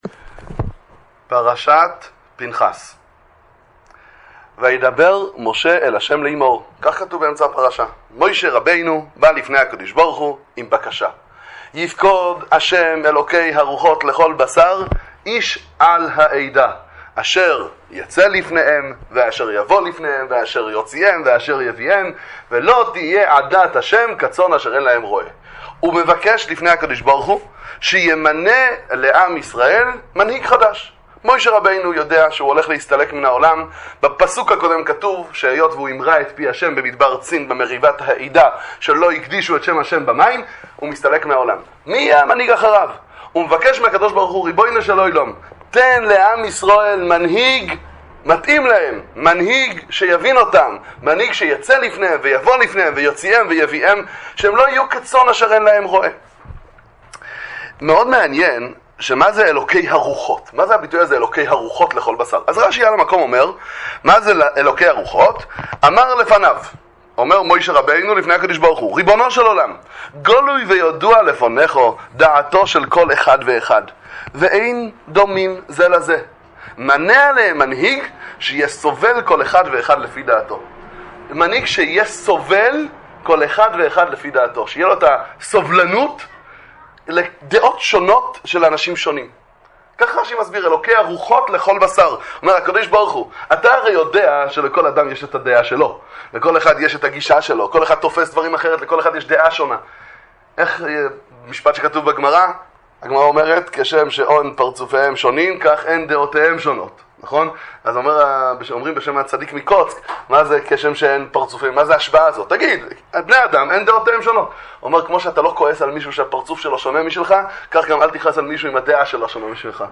דבר תורה קצר על פרשת השבוע, שיעורי תורה וחסידות